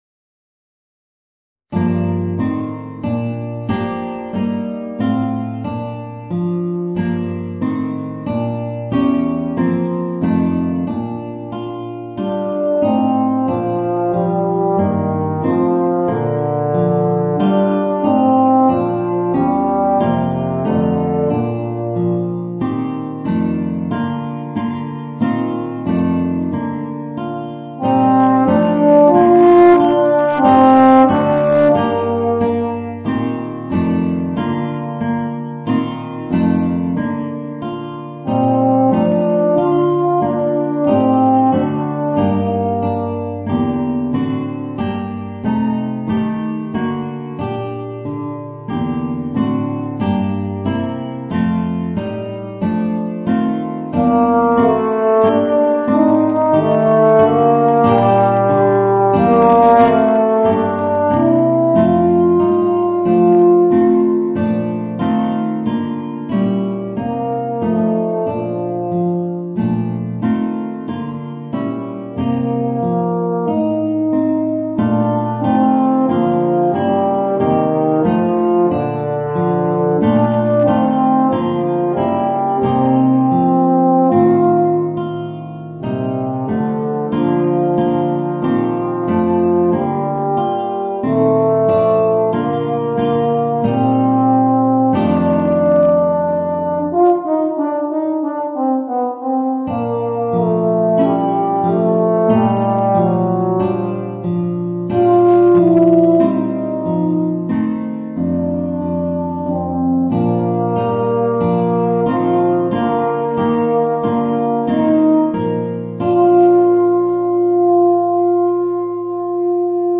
Voicing: Euphonium and Guitar